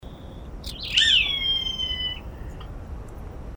Chimango Caracara (Daptrius chimango)
Life Stage: Adult
Location or protected area: Reserva Ecológica Costanera Sur (RECS)
Condition: Wild
Certainty: Recorded vocal